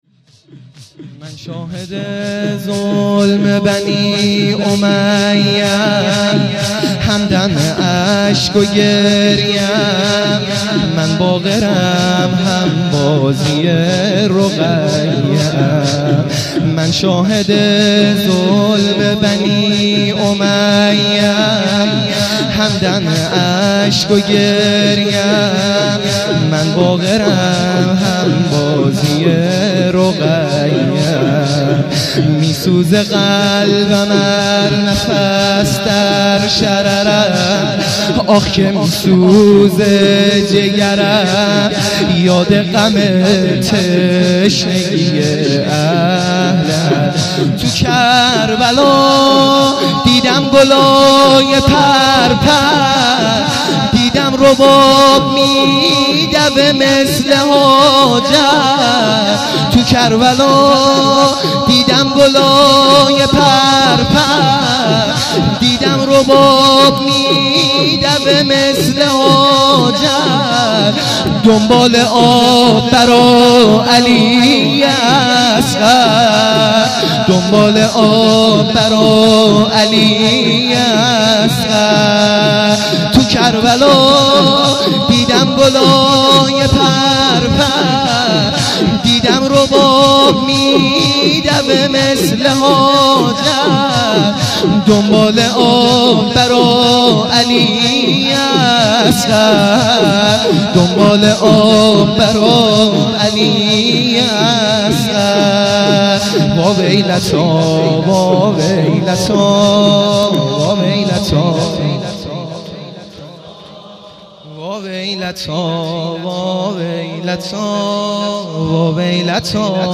شور | من شاهده ظلم بنی امیه
شهادت امام باقر علیه السلام